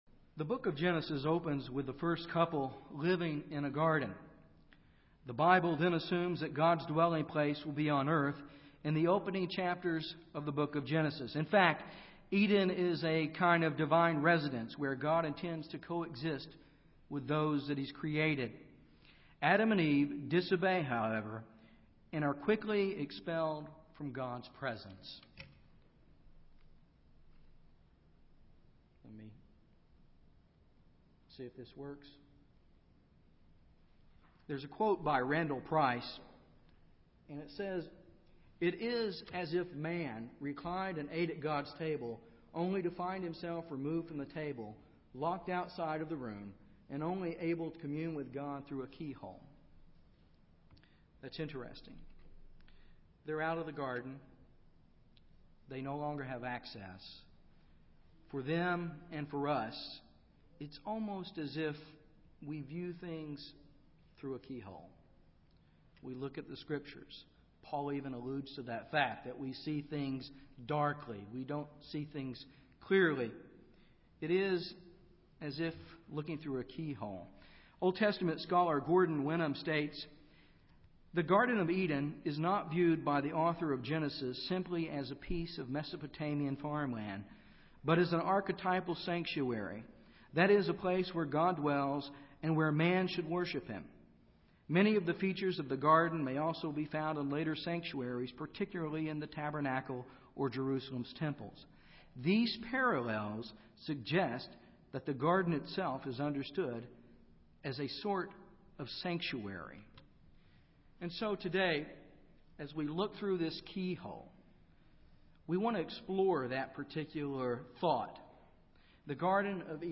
UCG Sermon Studying the bible?
Given in Dallas, TX